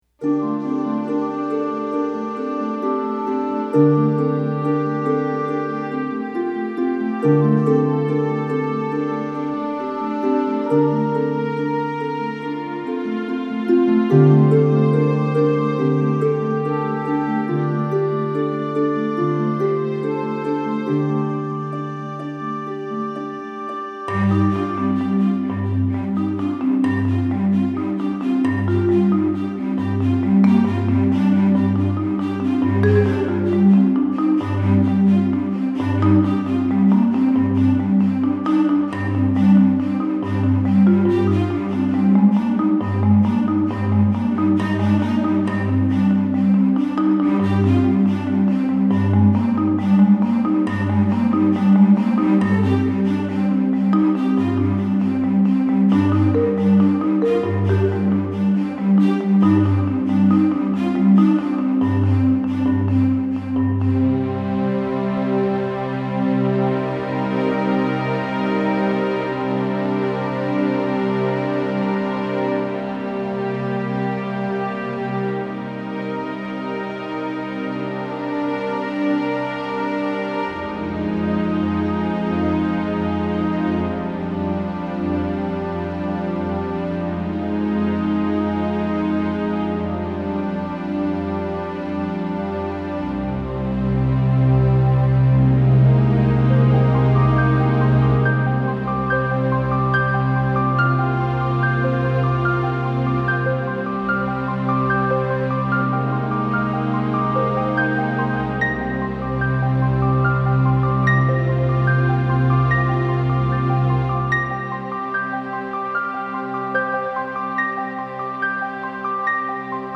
(Film Score, Dramatic, Moody, Evocative)